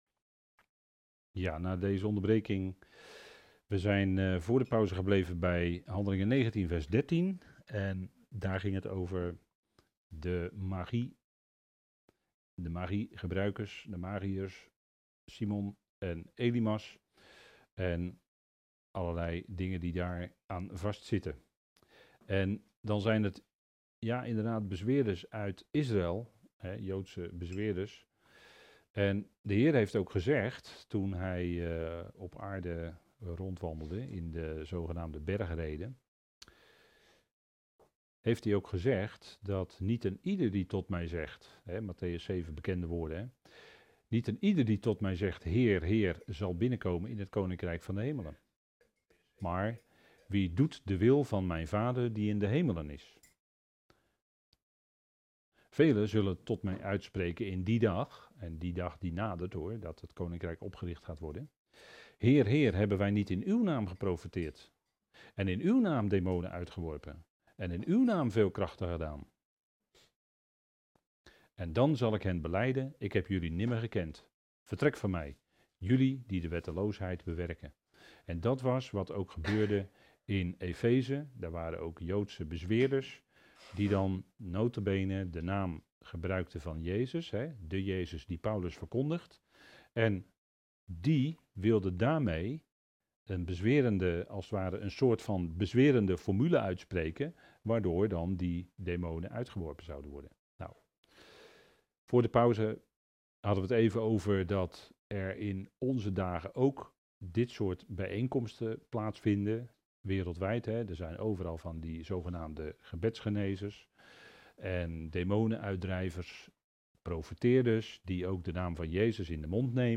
Wat laat God zien 5 oktober 2025 Studies, Spreekbeurten God spreekt van Zichzelf, Hij laat allerlei zien, wat Hij in de loop van de eonen doet.